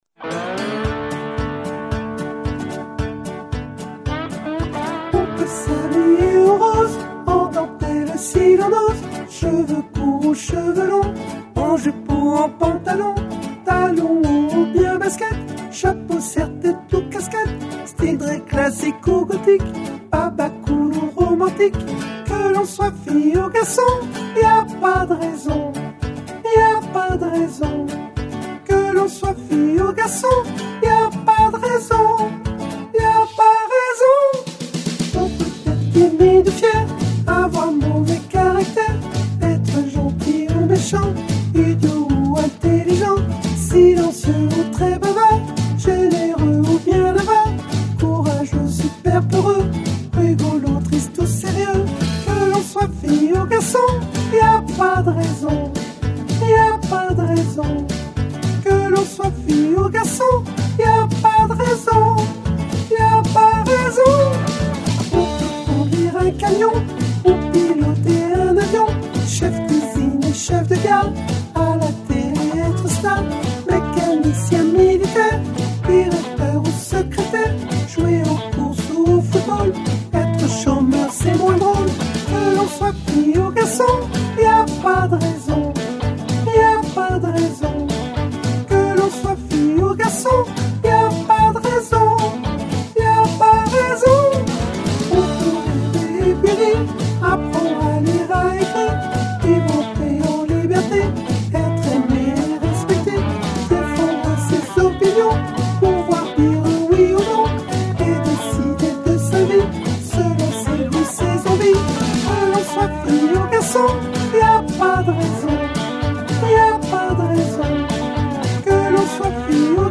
Soprane